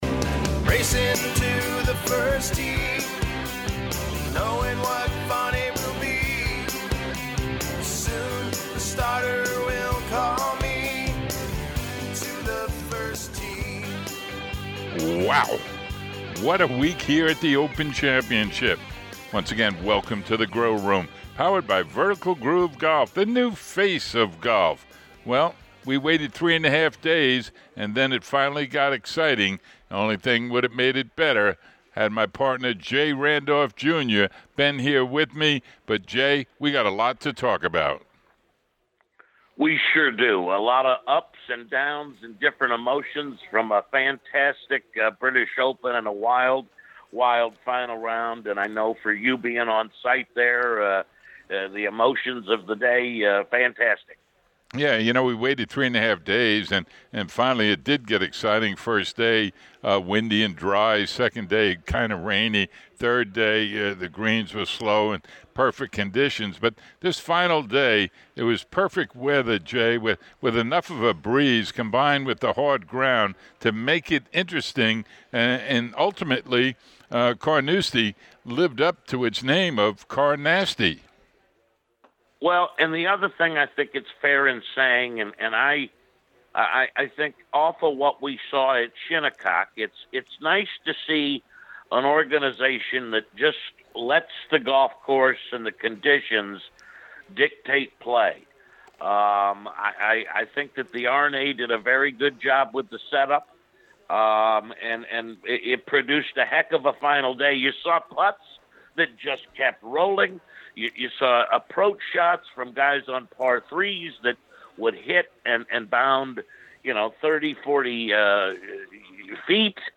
we close out this special edition with part of the Champion Golfer of the Year's winning press conference.